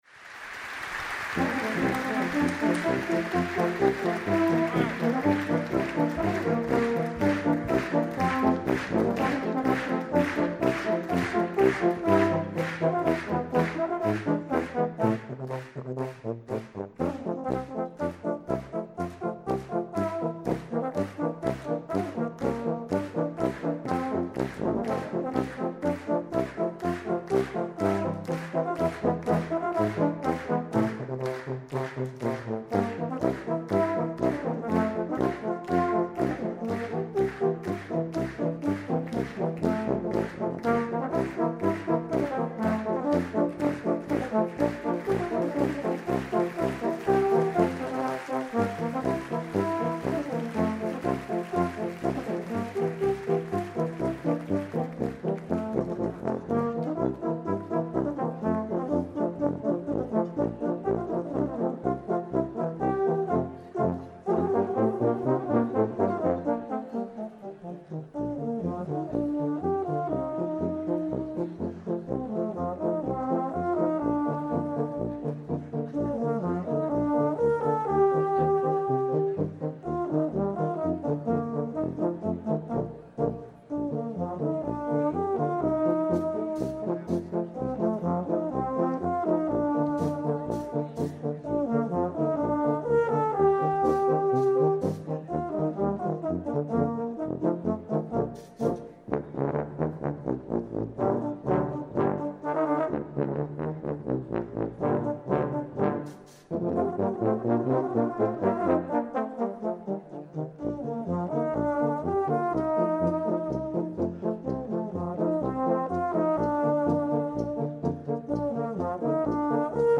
For Tuba Quartet (EETT), Composed by Traditional.